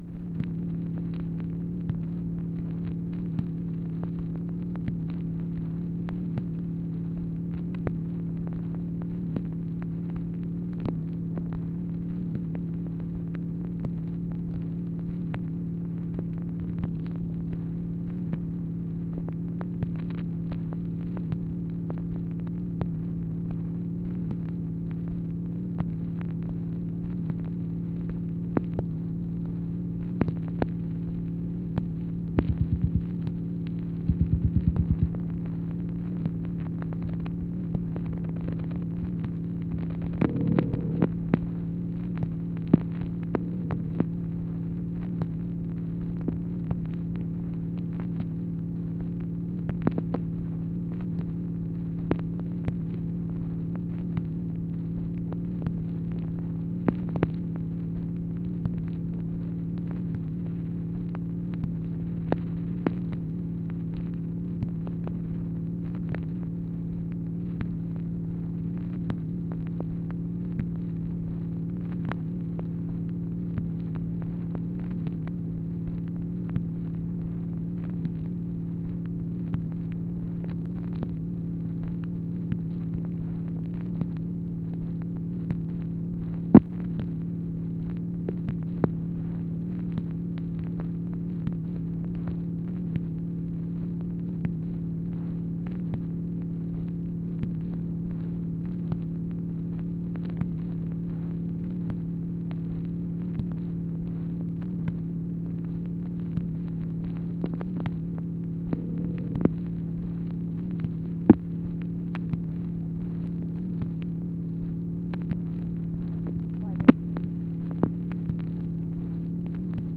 MACHINE NOISE, January 6, 1964
Secret White House Tapes | Lyndon B. Johnson Presidency